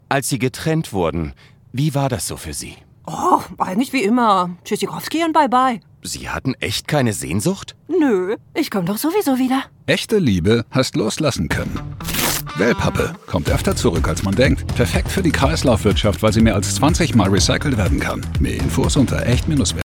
Lektor